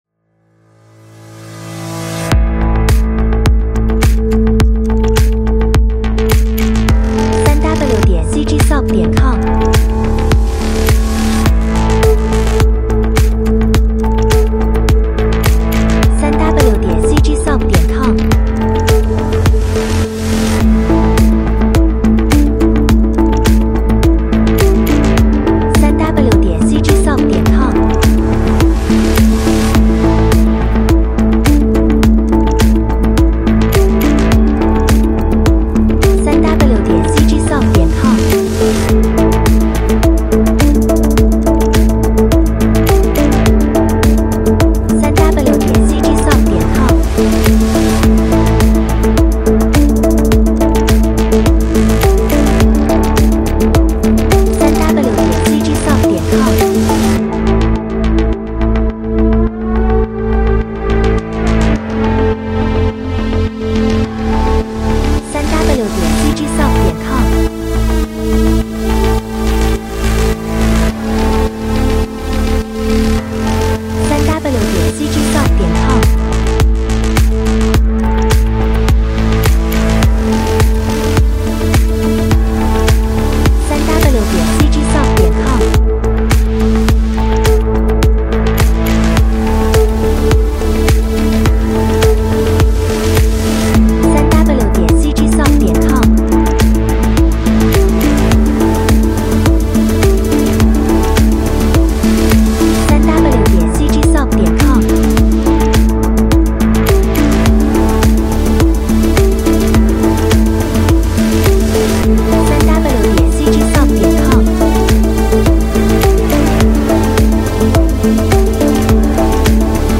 主要仪器：环境和复古波合成器，垫，电子钢琴，电子鼓，打击乐和SFX
采样率:16-位立体声， 44.1千赫兹
克里思（BPM）:105